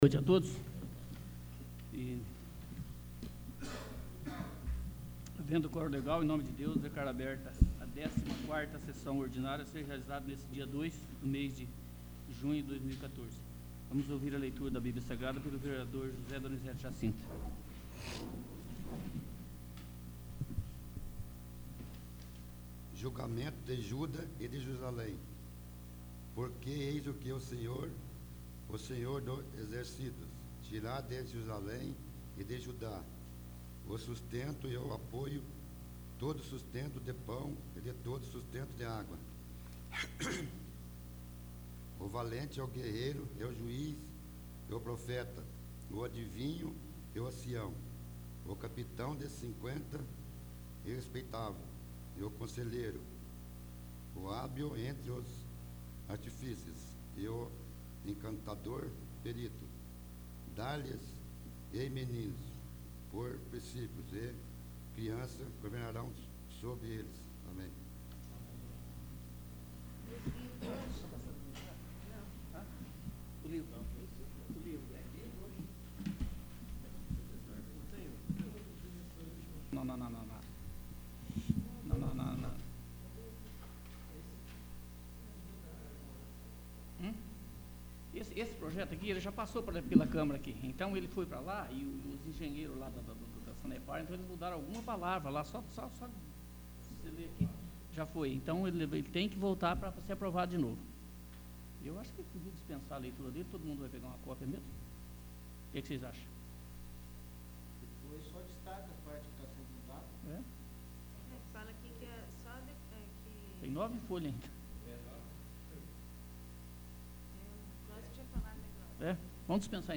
14º. Sessão Ordinária